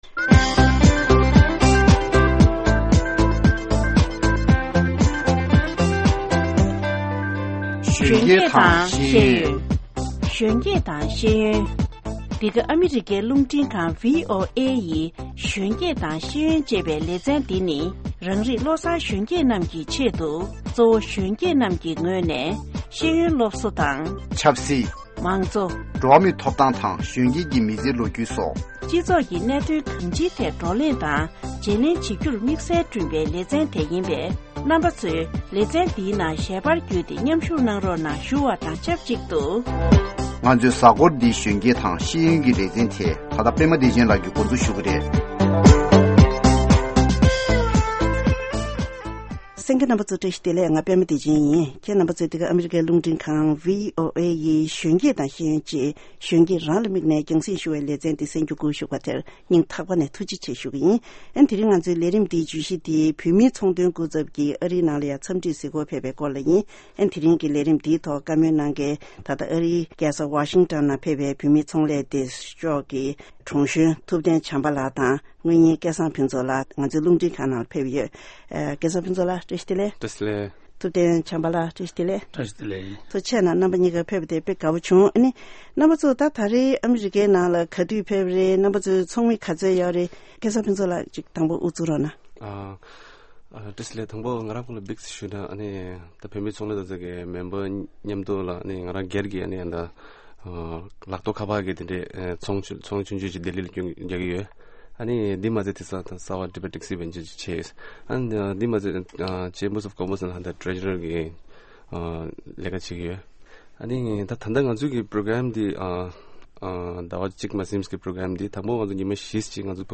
འདི་གའི་རླུང་འཕྲིན་ཁང་ནང་གཞོན་སྐྱེས་དང་ཤེས་ཡོན་ལས་རིམ་ཆེད་ཀྱི་གནས་འདྲིར་དང་ལེན་གནང་བའི་ལེ་ཚན་དེ་གསན་གྱི་རེད།